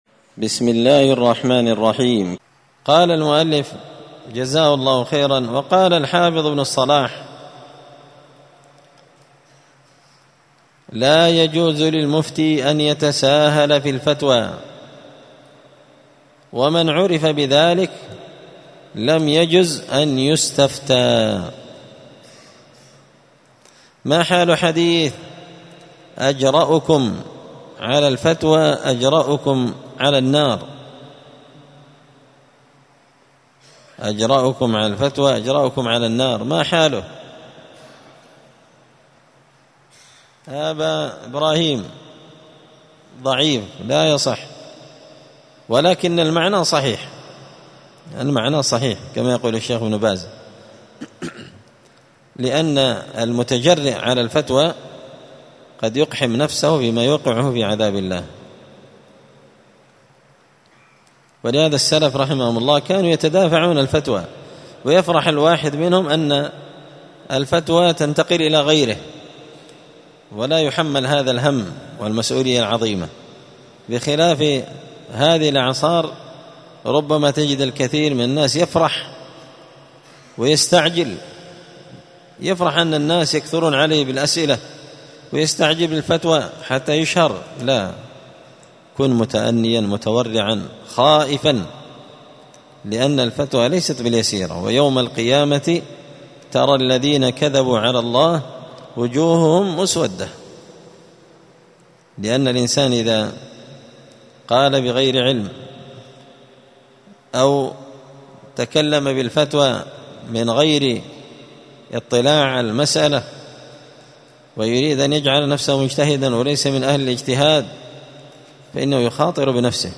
*الدرس الخامس بعد المائة (105) تابع للأدب الثالث والتسعون الحلم والثبات ومجانبة التسرع والحدة*
الخميس 27 ربيع الأول 1445 هــــ | الدروس، النبذ في آداب طالب العلم، دروس الآداب | شارك بتعليقك | 70 المشاهدات